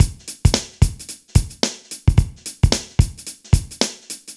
Index of /90_sSampleCDs/AKAI S6000 CD-ROM - Volume 4/Others-Loop/BPM110_Others2